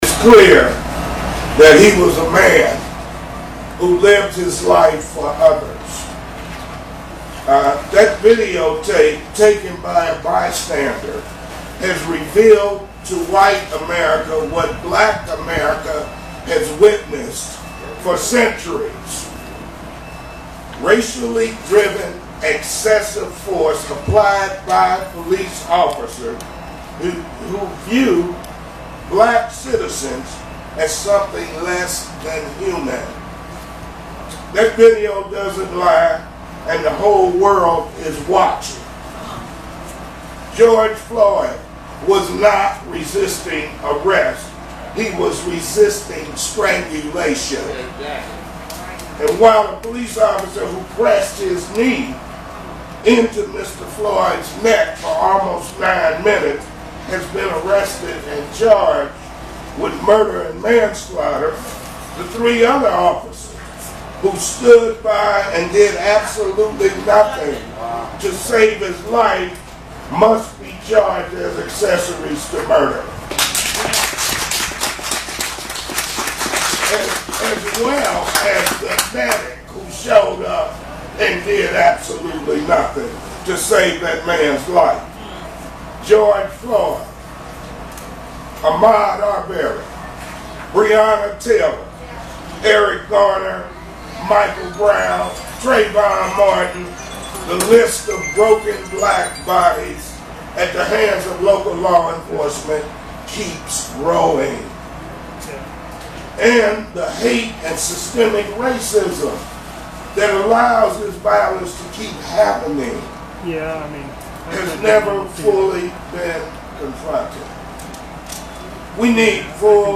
U.S. Rep. Lacy Clay, D-St. Louis, spoke Saturday at an Urban League food distribution event in Florissant, which is a north St. Louis County suburb.